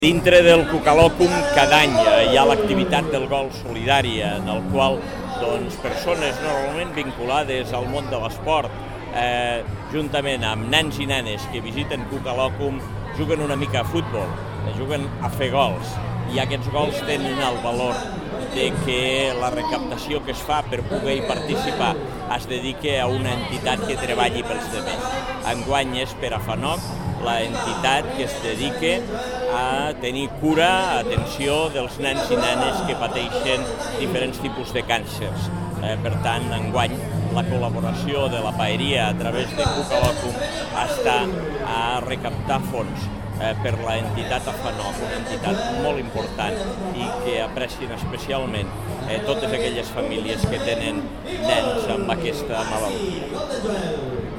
tall-de-veu-de-lalcalde-angel-ros-sobre-la-recaptacio-del-gol-solidari-a-cucaesport-destinada-a-afanoc